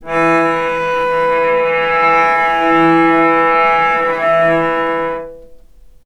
vc_sp-E3-mf.AIF